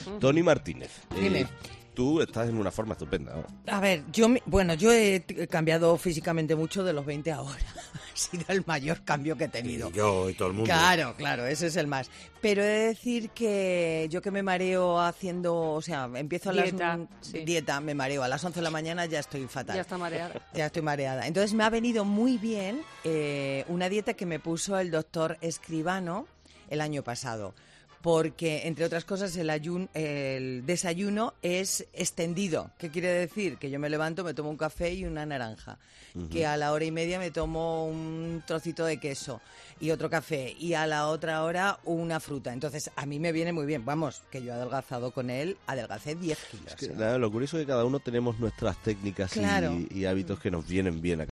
Descubre, en el siguiente audio, la dieta que te permite comer cada poco tiempo, que ha contado este fósforo en COPE.